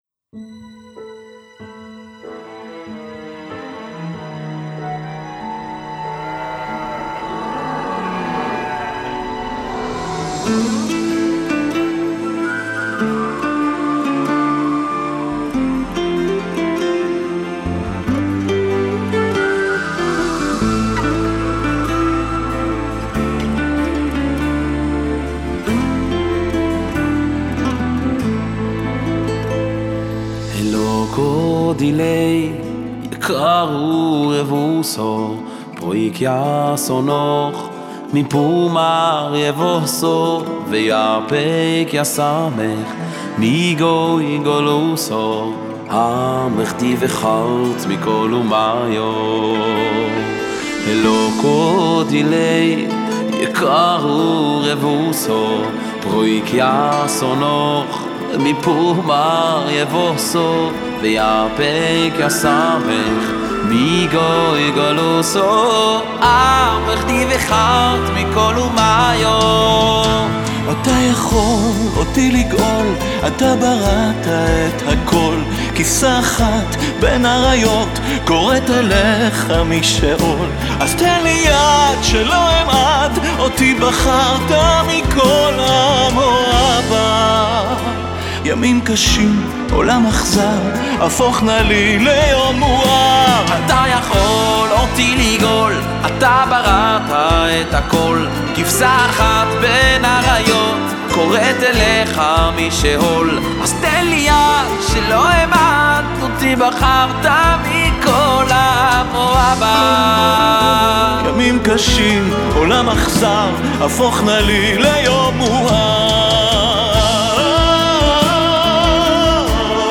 הזמר החסידי